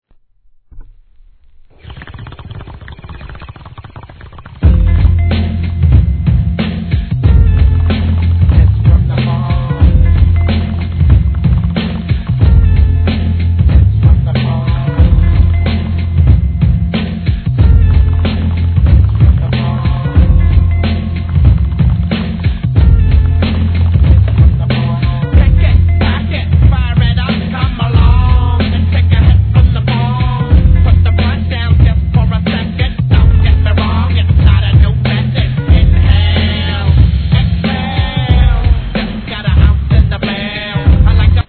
HIP HOP/R&B
地を這うベースライン、疾走感を煽るドラム、煙々しいサウンド